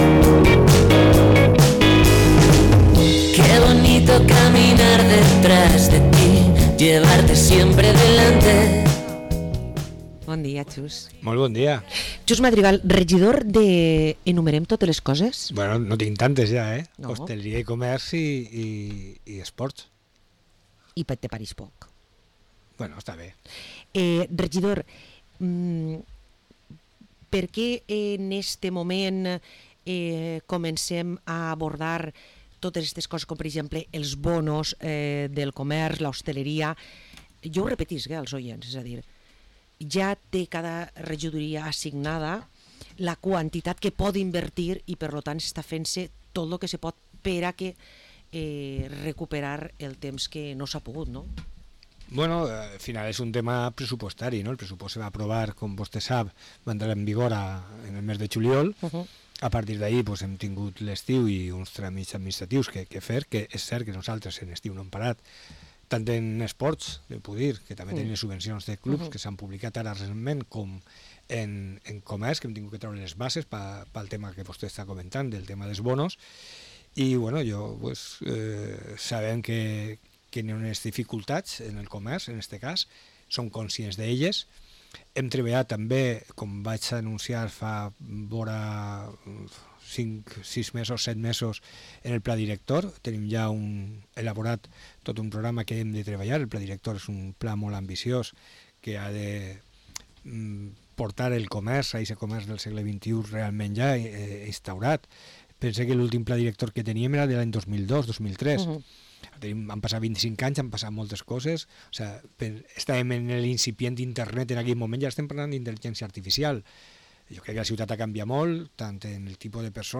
Parlem amb el regidor Xus Madrigal